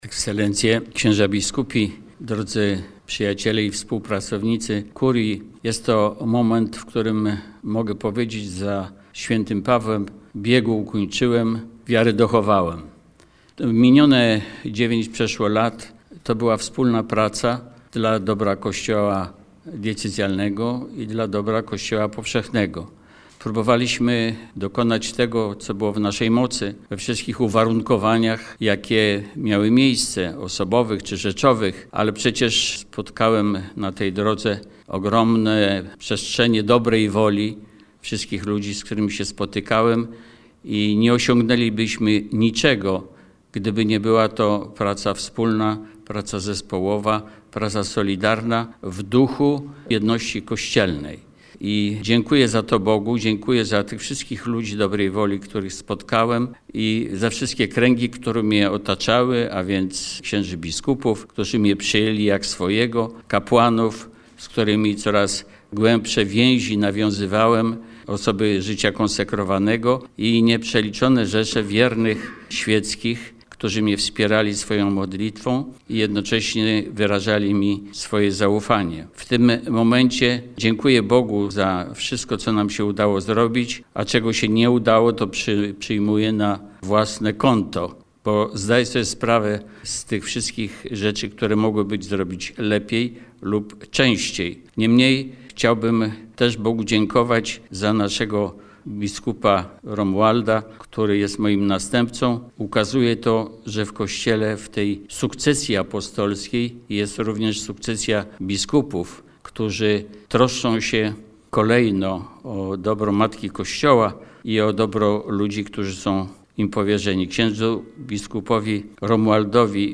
Do wysłuchania słowa podziękowań abp. Hosera, wygłoszone podczas uroczystości w kurii warszawsko-praskiej.